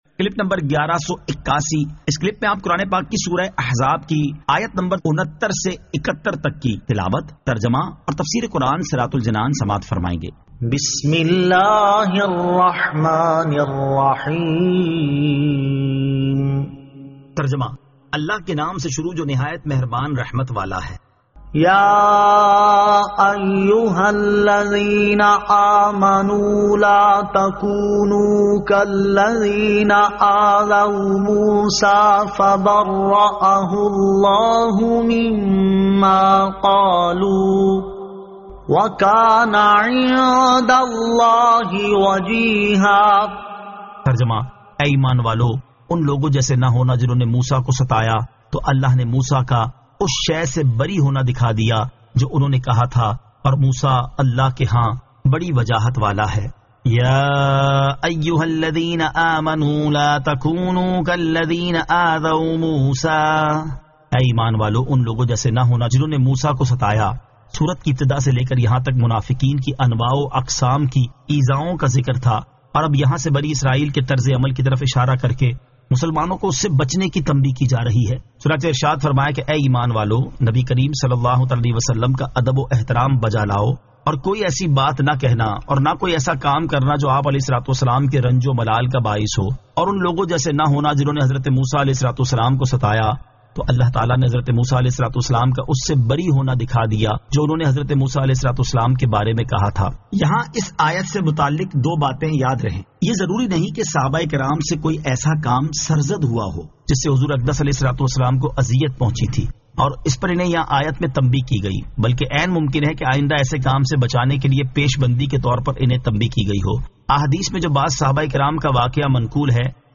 Surah Al-Ahzab 69 To 71 Tilawat , Tarjama , Tafseer
2023 MP3 MP4 MP4 Share سُوَّرۃُ الٗاحٗزَاب آیت 69 تا 71 تلاوت ، ترجمہ ، تفسیر ۔